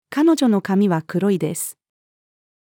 彼女の髪は黒いです。-female.mp3